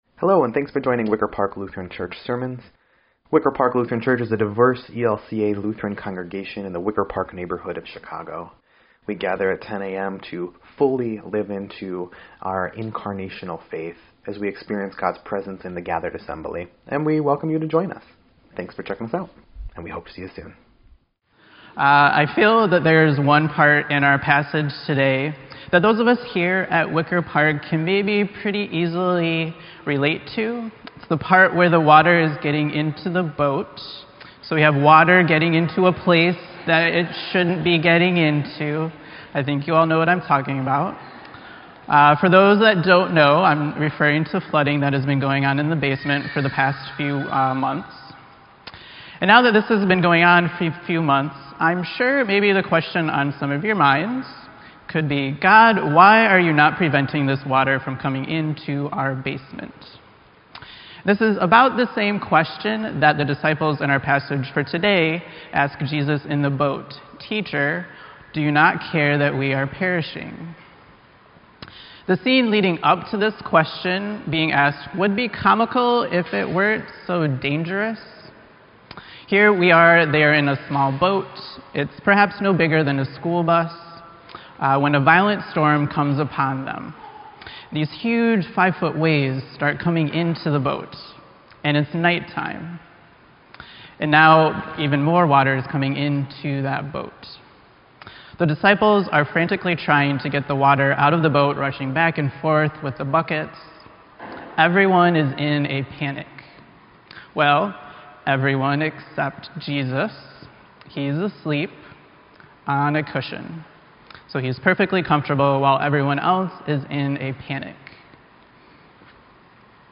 Sermon_6_24_18_EDIT.mp3